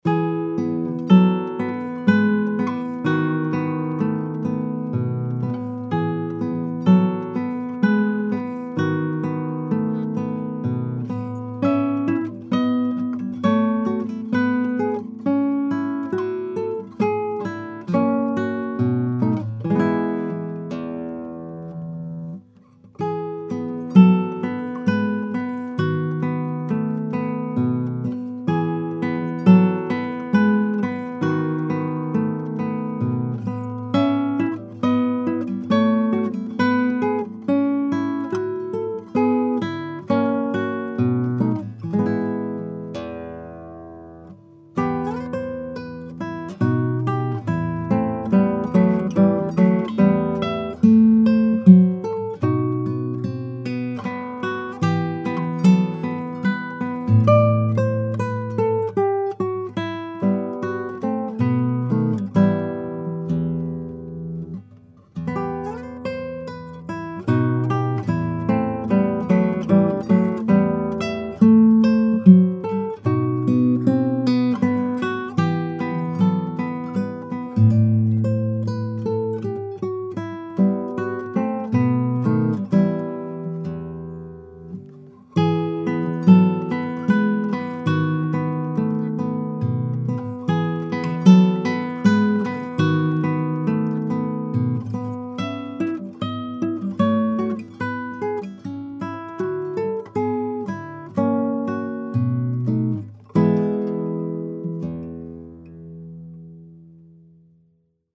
pauferro1a-lagrima.mp3